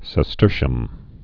(sĕ-stûrshəm, -shē-əm)